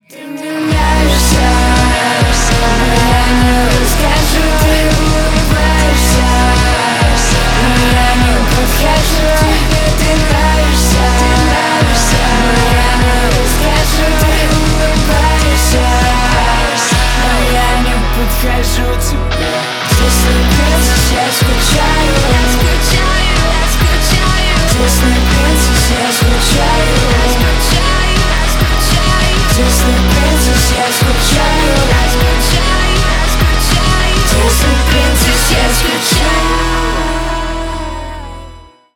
• Качество: 320 kbps, Stereo
Рок Металл
спокойные
тихие